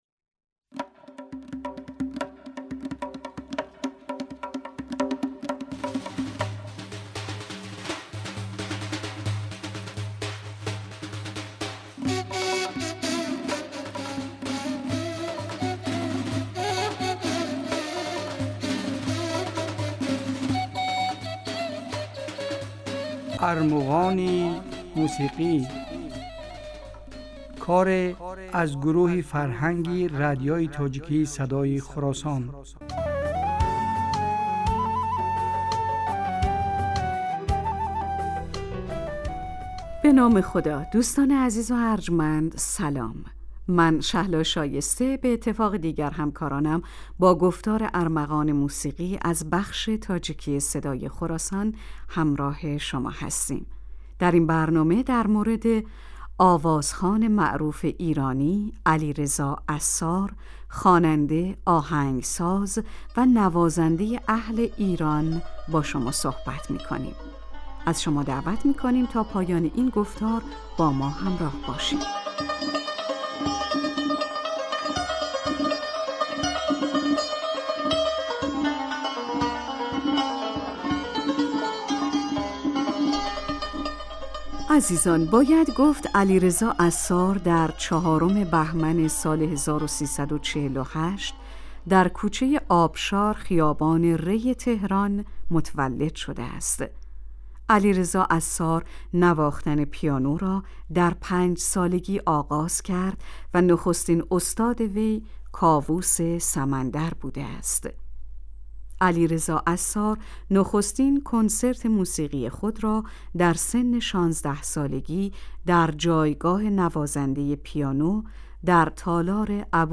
Армуғони мусиқӣ асари аз гурӯҳи фарҳанги радиои тоҷикии Садои Хуросон аст. Дар ин барномаҳо кӯшиш мекунем, ки беҳтарин ва зеботарин мусиқии тоҷикӣ ва эрониро ба шумо пешкаш кунем.